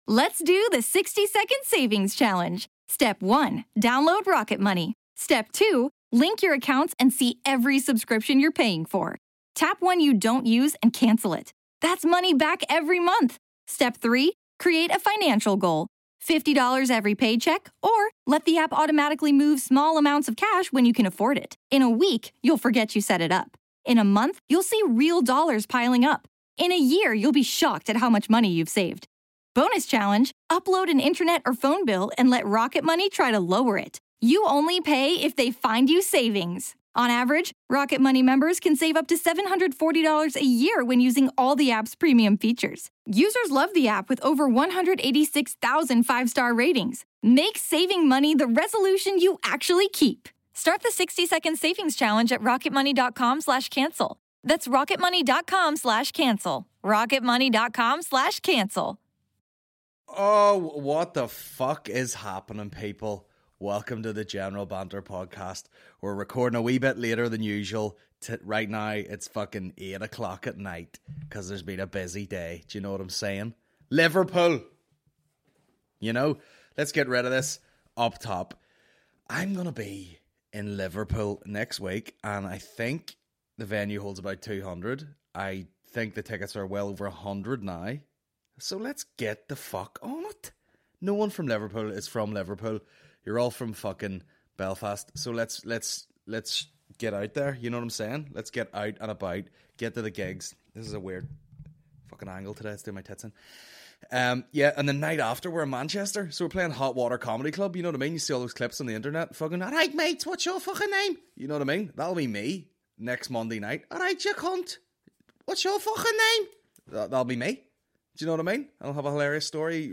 Comedy podcast